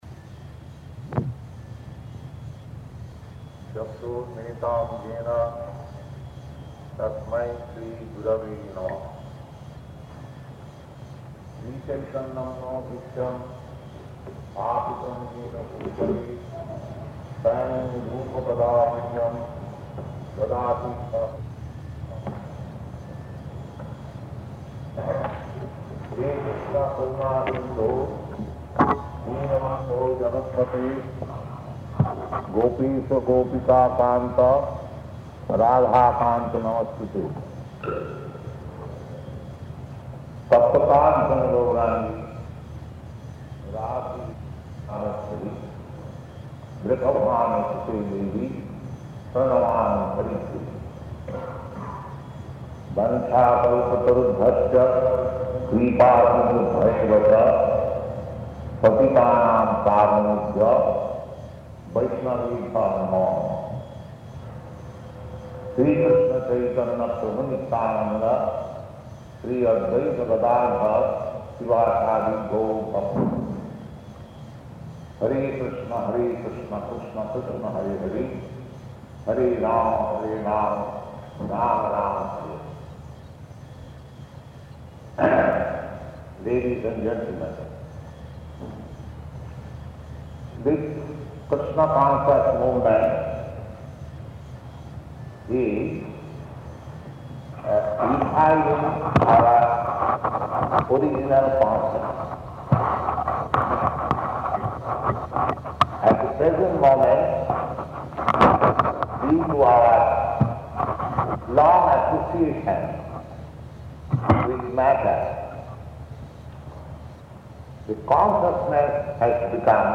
Type: Lectures and Addresses
Location: Montreal
[chants maṅgalācaraṇa prayers]